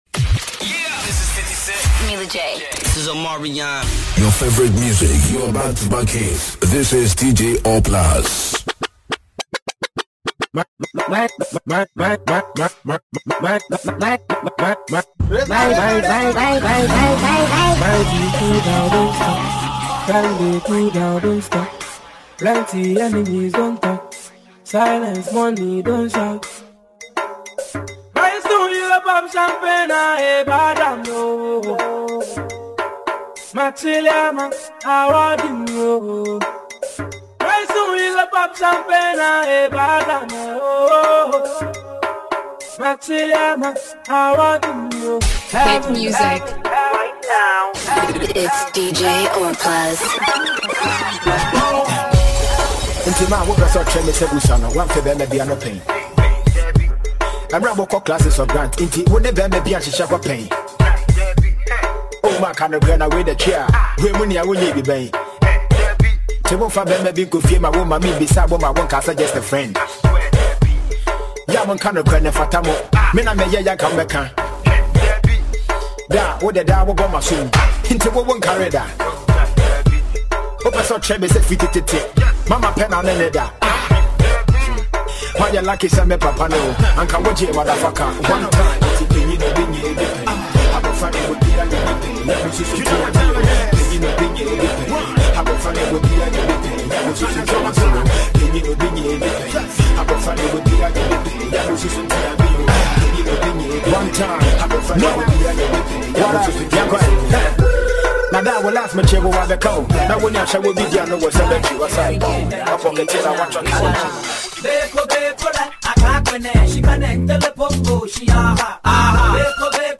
Genre: Mixtape Release Format Type
brings back the old school vibes with a modern twist.